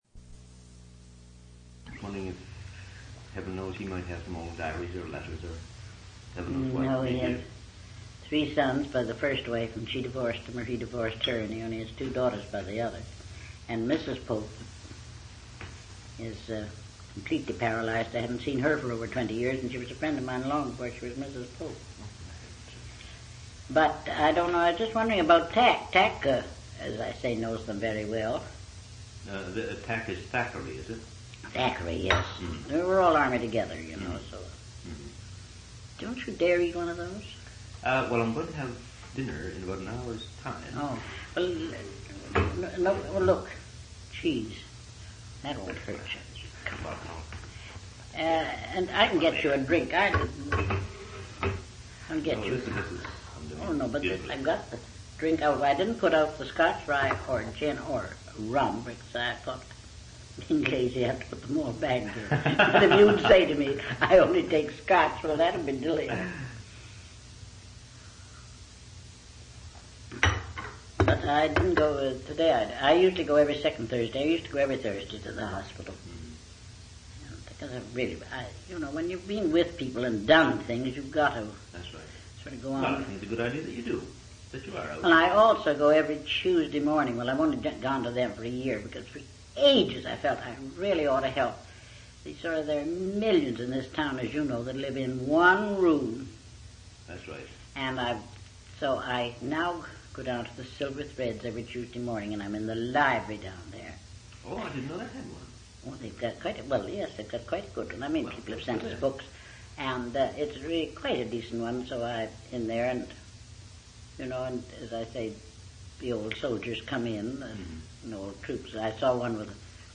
One original sound tape reel (ca. 80 min.) : 1 7/8 ips, 2 track, mono.
oral histories (literary genre) sound recordings reminiscences interviews
Transferred from audio reel to audio cassette between 1987-1997.